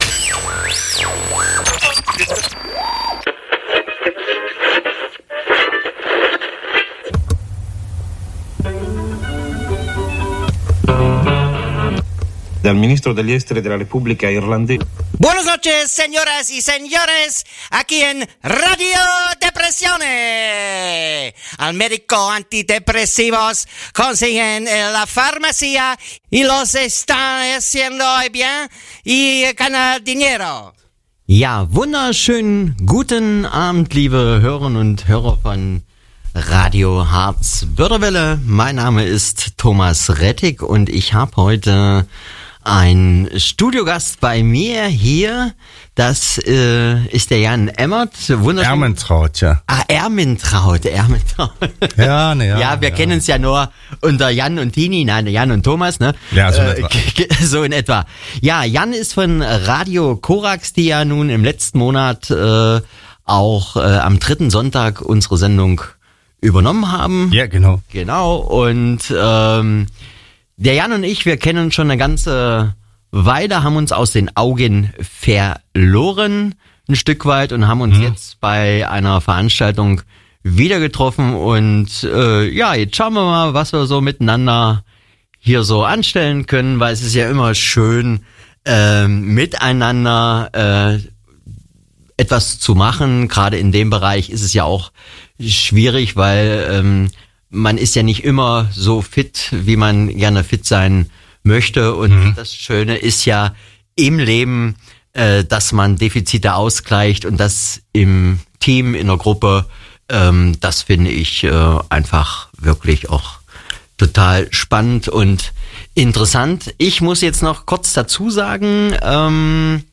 radio hbw ist das Lokalradio für den Harz und die Börde.
Es geht um psychische Störungen und Erkrankungen, Behandlungsmöglichkeiten und Anlaufpunkte für Betroffene. Dazu gibt es regelmäßig Interviews mit Fachleuten und Betroffenen, Buchtipps und Umfragen zu bestimmten Themen.